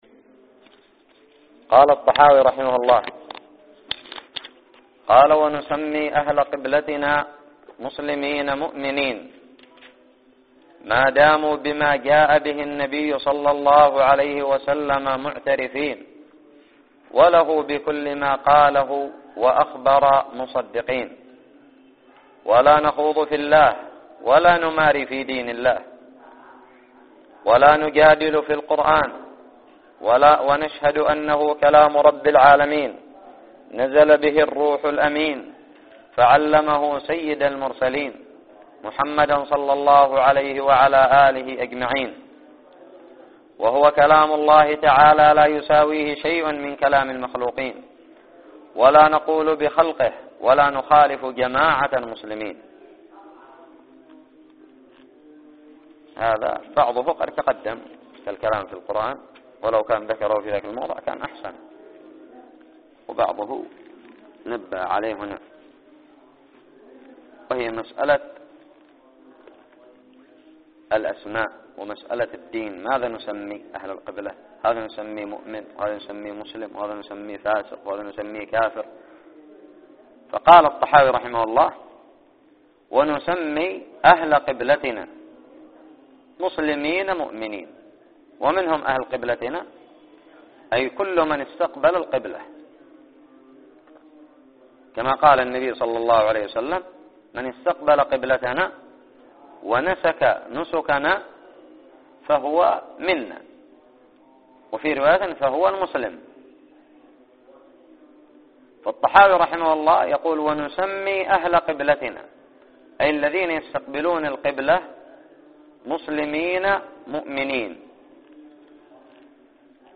الدرس السابع والعشرون من شرح العقيدة الطحاوية
ألقيت في دار الحديث بدماج